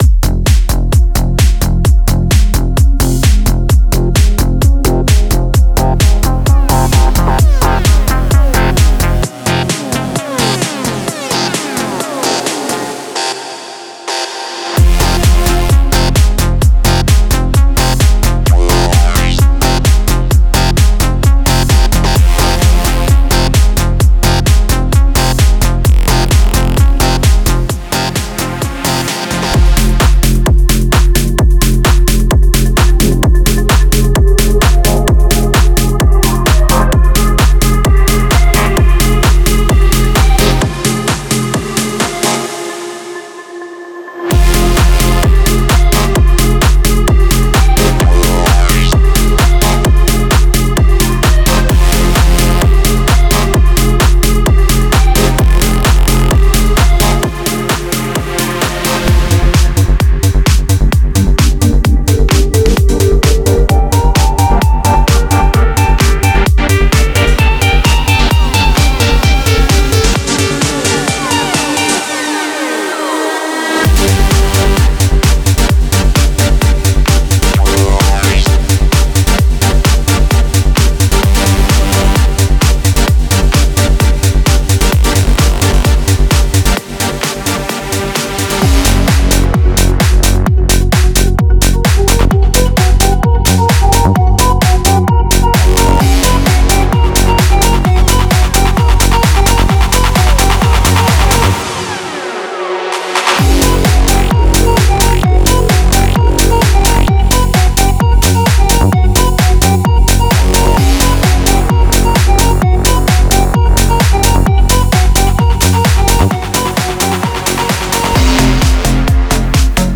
Electro House Tech House
pumping grooves, crunchy percussion, and catchy hooks
The sound is powerful, musical, and bold.